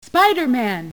(Links throughout this page point to mp3s from the game.)
The voice of a woman. A woman who sounded like she belonged on a default answering machine message.